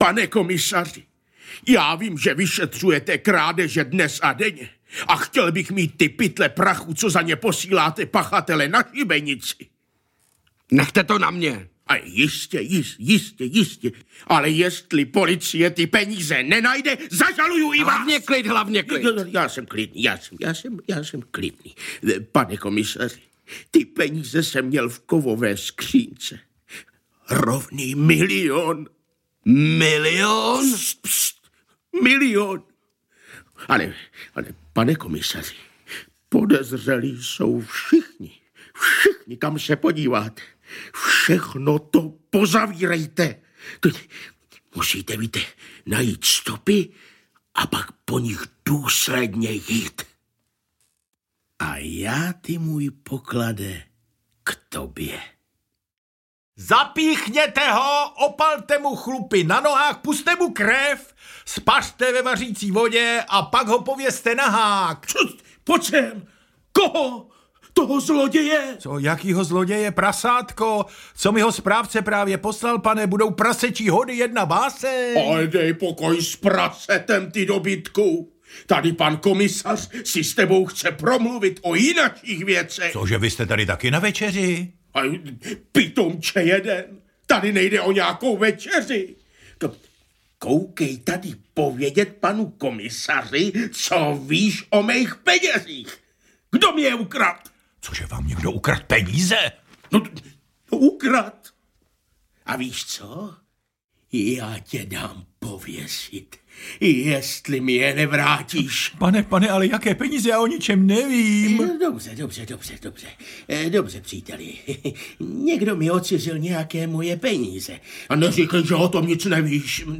Lakomec audiokniha
Ukázka z knihy